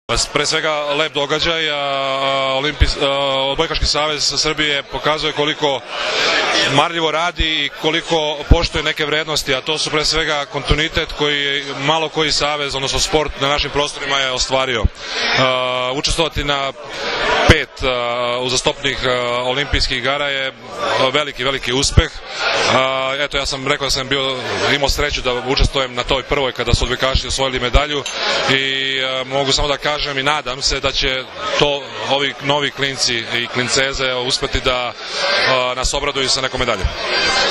Odbojkaški savez Srbije je večeras u beogradskom hotelu „M“ priredio svečanost pod nazivom „Olimpijski kontinutitet“ povodom plasmana ženske i muške seniorske reprezentacije na Olimpijske igre u Londonu.
IZJAVA VLADE DIVCA